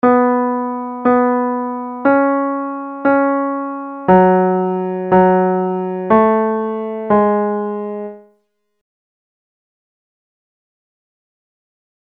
Key written in: E Major
Comments: Nice gentle reassuring tag.
Each recording below is single part only.